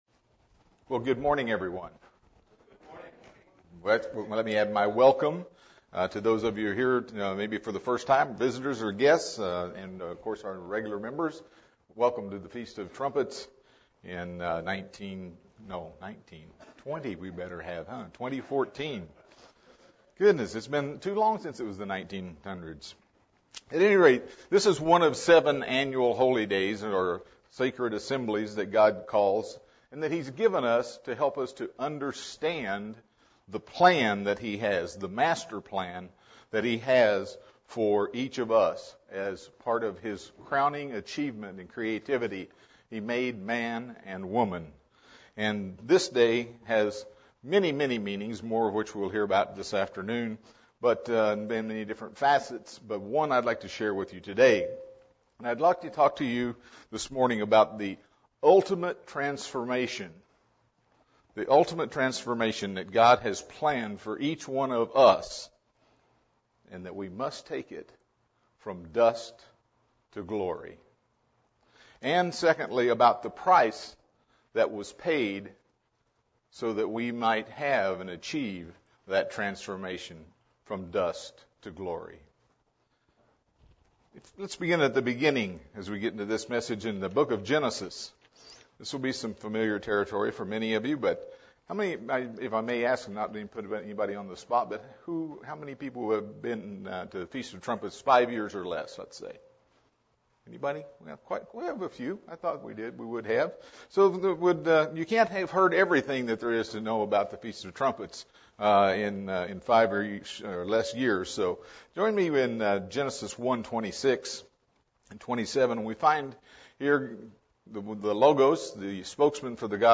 Given in San Diego, CA